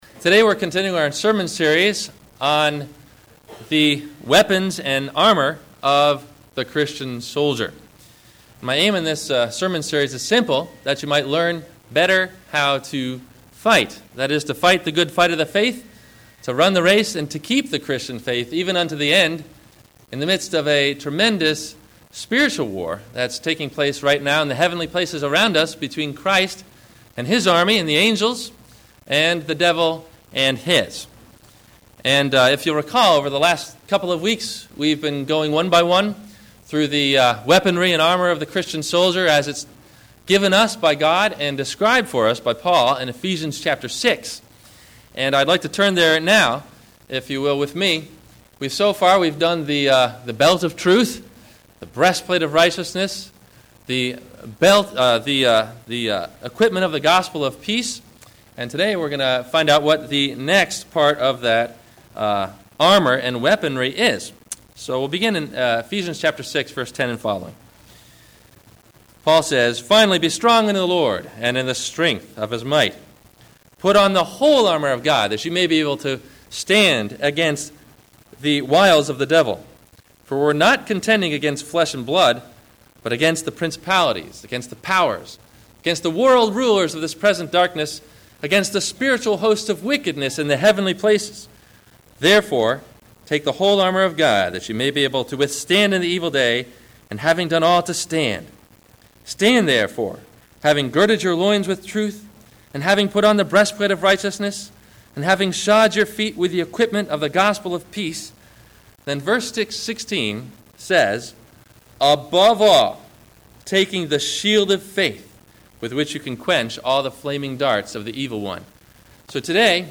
The Shield of Faith – Spiritual Warfare – WMIE Radio Sermon – September 01 2014